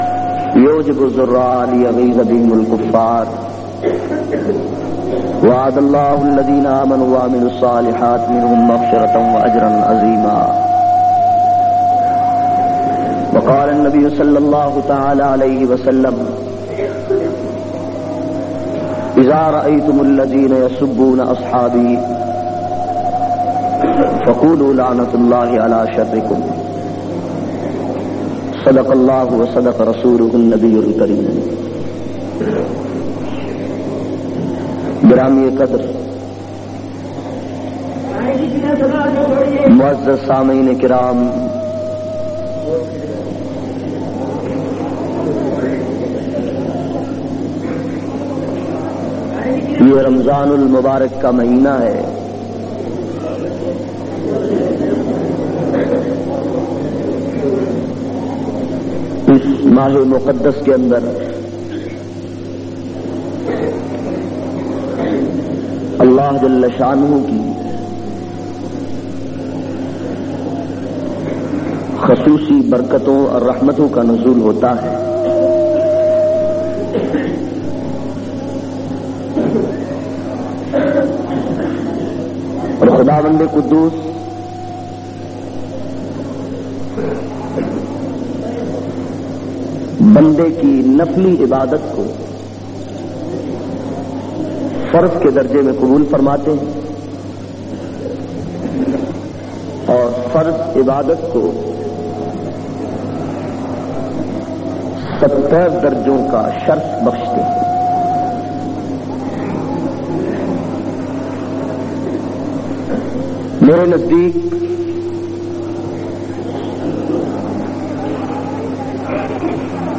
12- Fazail e Ramzan Shan e Rasool o diffa e Sahaba Jumma Jhang.mp3